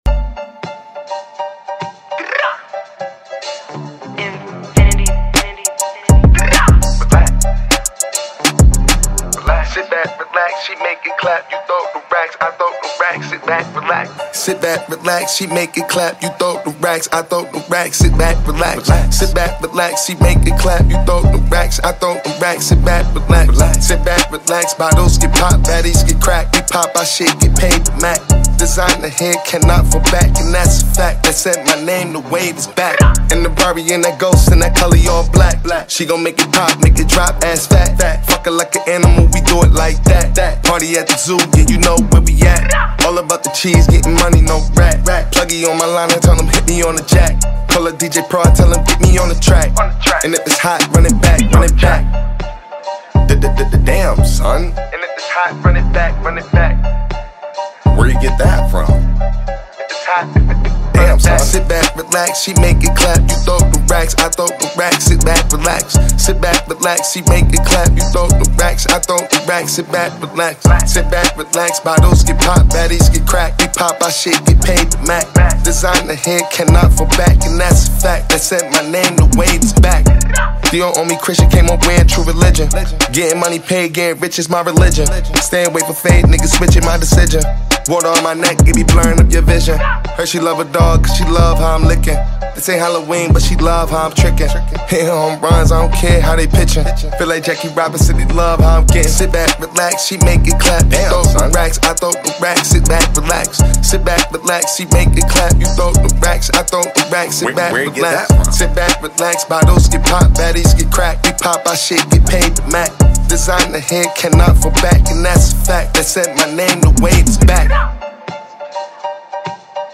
Its simple flow keeps things enjoyable every time.